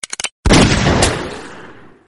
Dzwonek - Załadowanie broni i strzał
Dzwięk załadowania broni i późniejszego strzału.
zaladowanie-i-strzal-z-broni.mp3